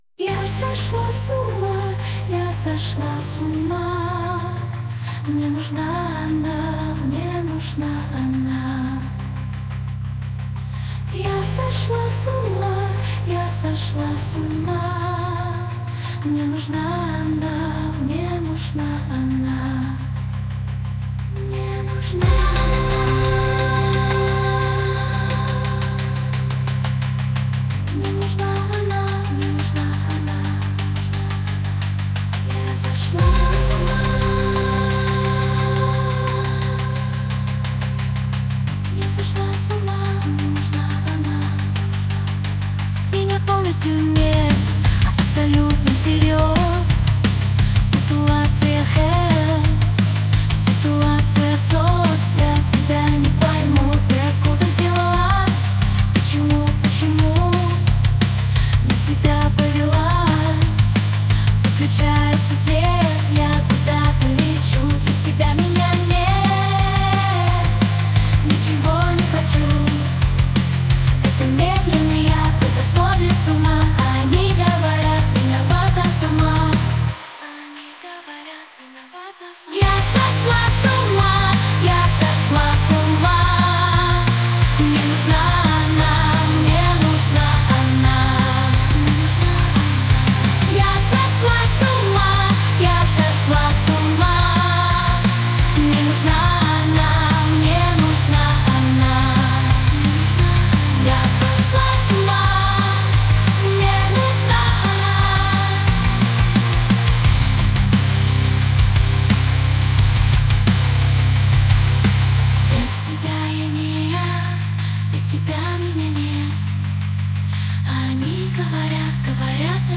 Anime opening